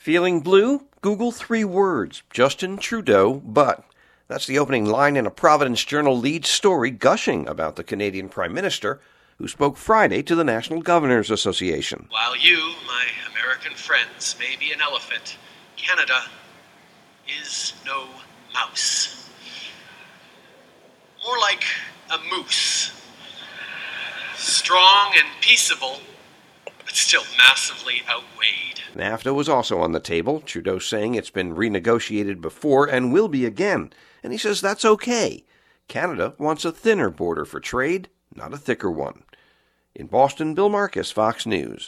(BOSTON) JULY 15 – A RHODE ISLAND PAPER GUSHING ABOUT THE POSTERIOR OF THE CANADIAN PRIME MINISTER. FOX NEWS RADIO’S